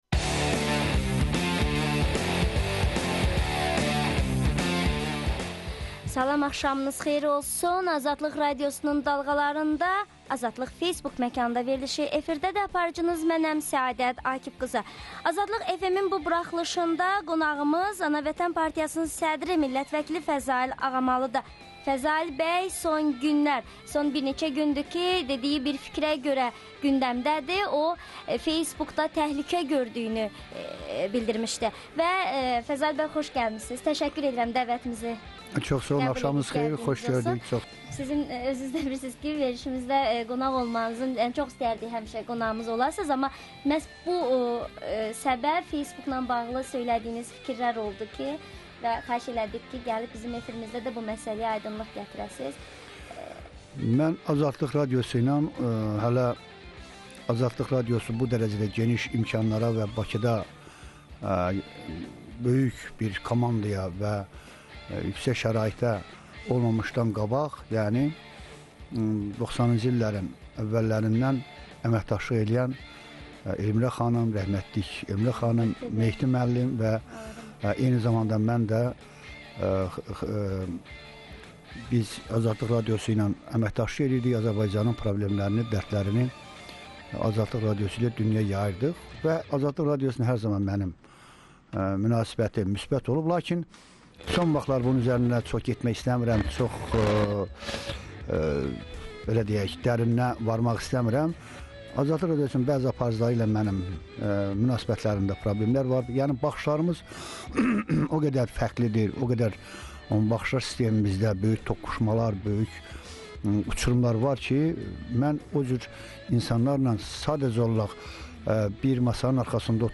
Martın 13-də AzadlıqFM proqramında millət vəkili Fəzail Ağamalı Facebook əhlinin və dinləyicilərin suallarına cavab verir